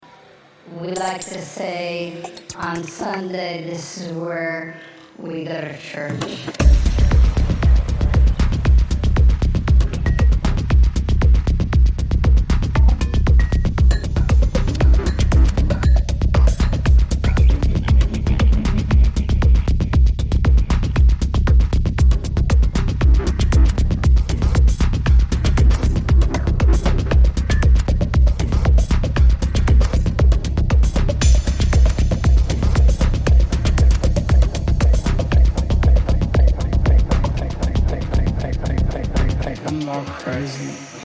Deep Deep bush tunes…